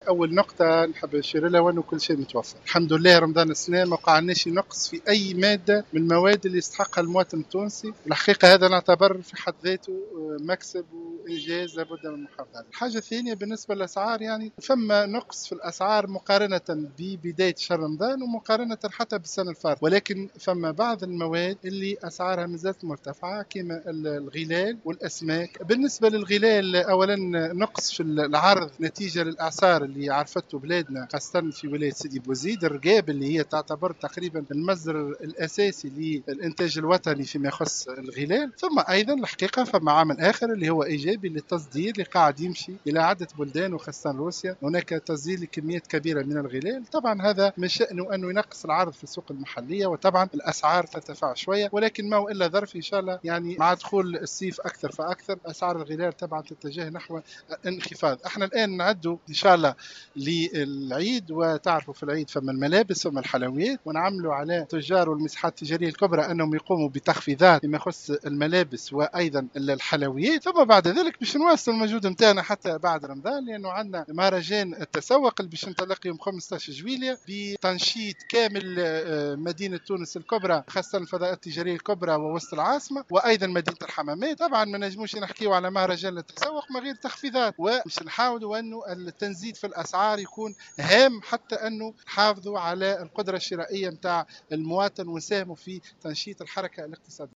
En marge d'une visite d'inspection dans un centre commercial à Tunis, le ministre a déclaré au micro du correspondant de Jawhara Fm qu'une réduction des prix a été enregistrée au niveau de certains produits à l'exception des fruits et du poisson.